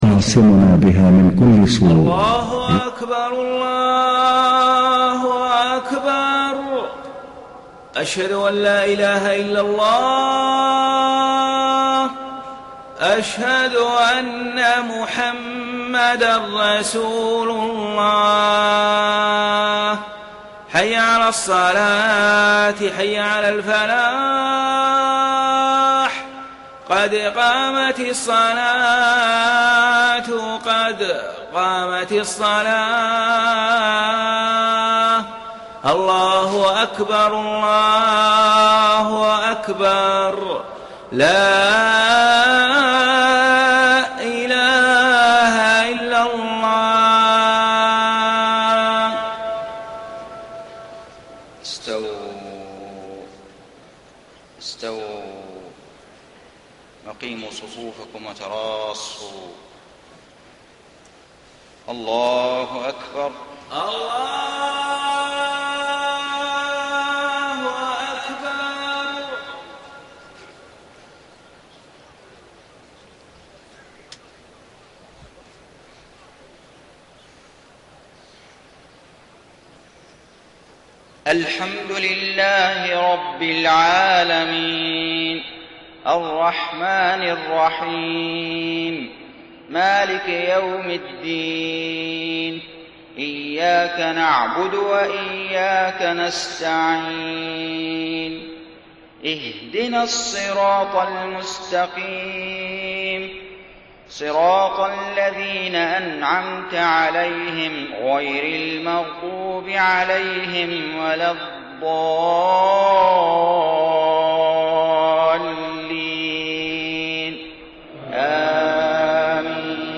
صلاة الفجر 14 ذو القعدة 1432هـ من سورة البقرة 67-82 > 1432 🕋 > الفروض - تلاوات الحرمين